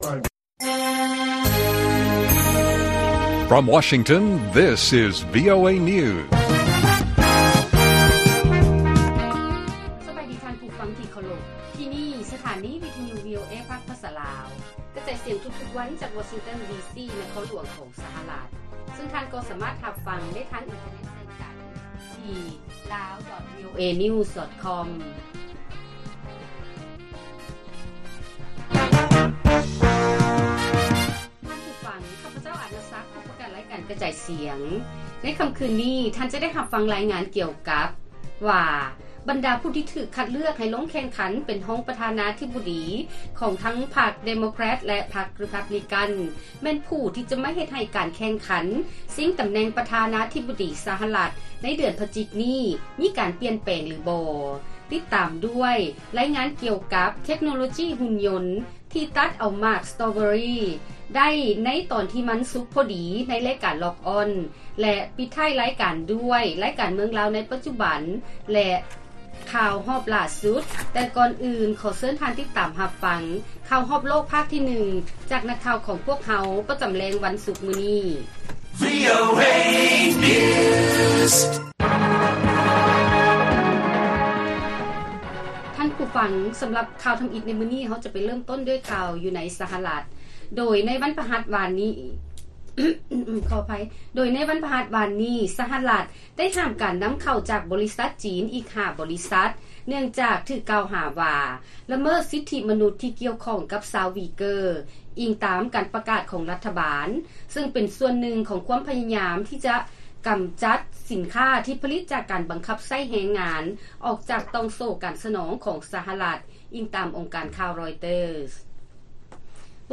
ລາຍການກະຈາຍສຽງຂອງວີໂອເອລາວ: ສະຫະລັດ ຫ້າມການນຳເຂົ້າຈາກບໍລິສັດຈີນອີກ 5 ບໍລິສັດ ເນື່ອງຈາກຖືກກ່າວຫາວ່າ ລະເມີດສິດທິມະນຸດທີ່ກ່ຽວຂ້ອງກັບຊາວ ວີເກີ